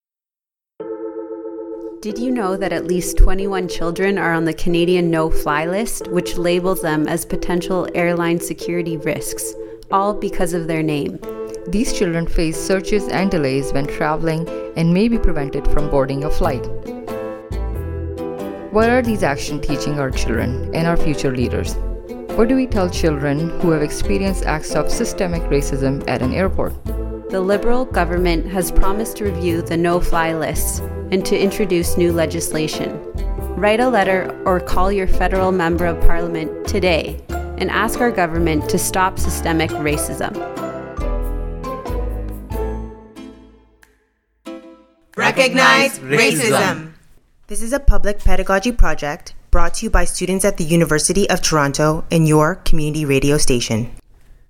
A public service announcement about children on the Canadian "no-fly list".
Recording Location: Toronto
Type: PSA